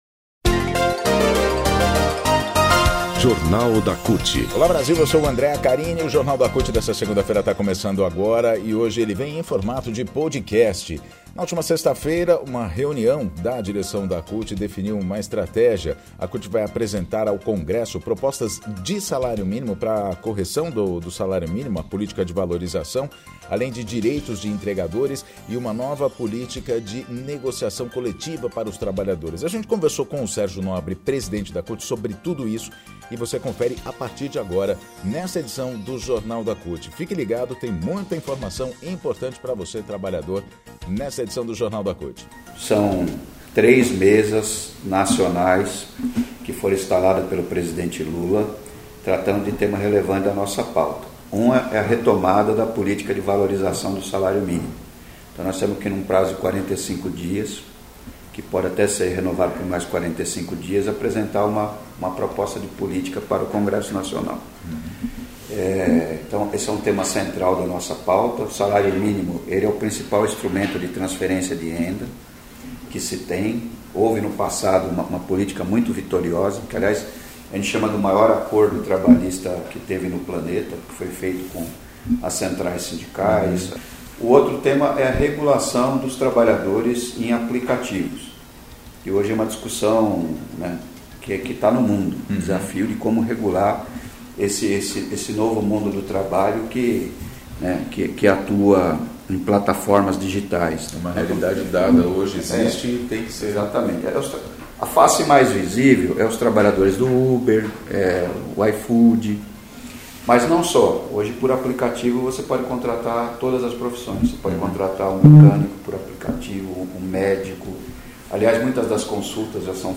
Em entrevista especial